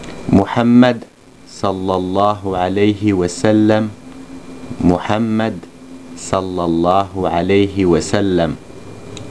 Aide à la prononciation de mots et formules arabes